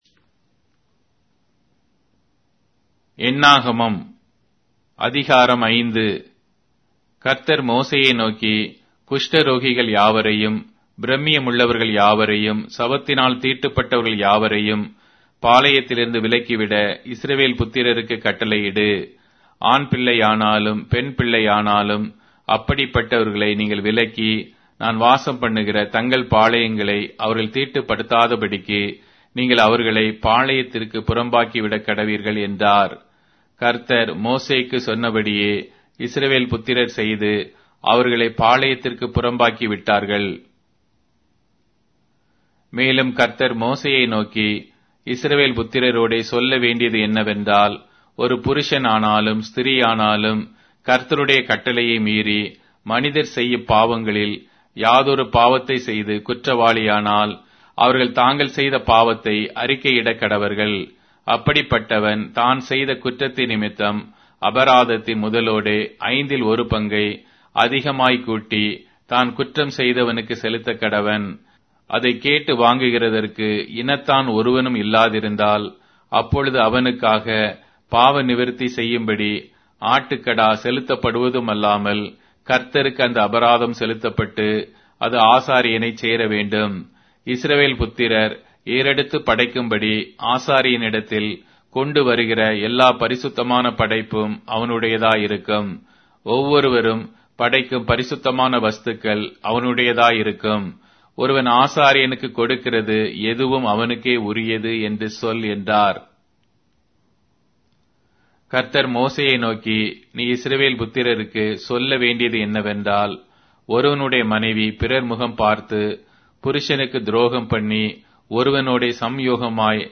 Tamil Audio Bible - Numbers 20 in Tov bible version